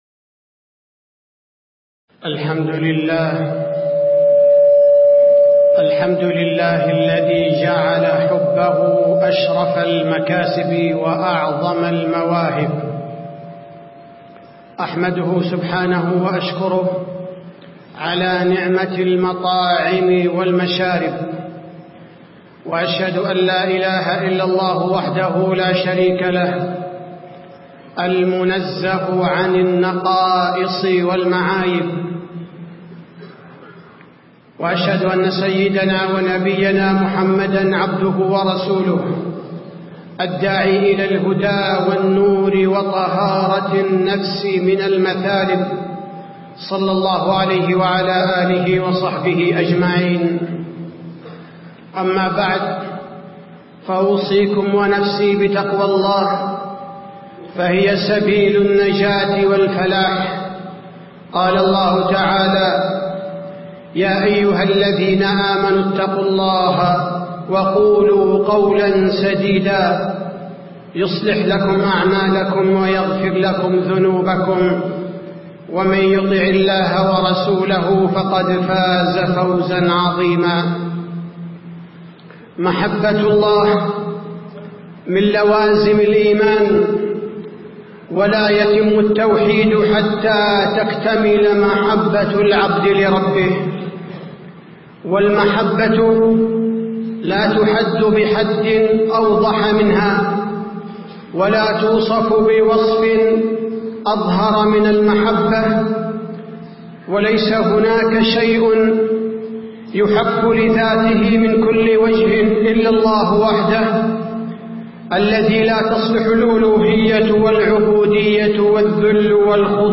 تاريخ النشر ١٥ رجب ١٤٣٧ هـ المكان: المسجد النبوي الشيخ: فضيلة الشيخ عبدالباري الثبيتي فضيلة الشيخ عبدالباري الثبيتي محبة الله تعالى